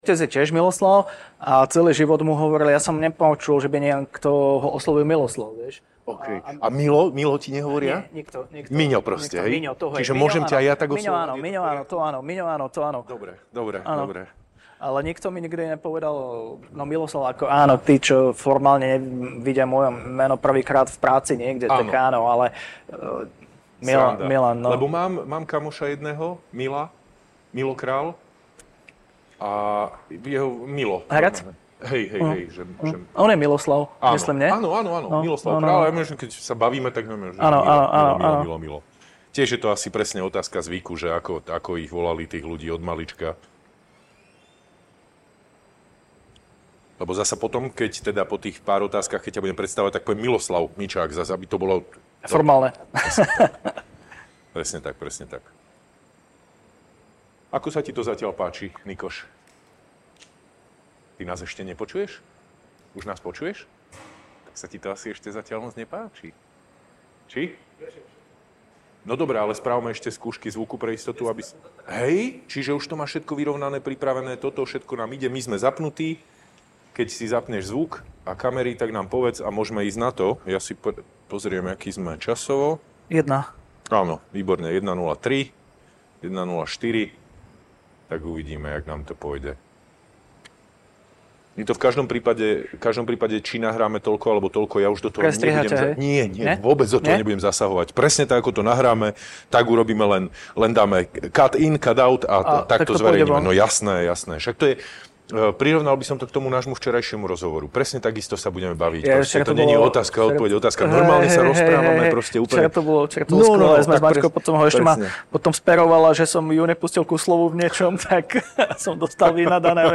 Jasné otázky – priame odpovede. Braňo Závodský a jeho hosť každý pracovný deň 10 minút po 12-tej naživo v Rádiu Expres na aktuálnu tému o veciach, o ktorých sa budete baviť pri obede.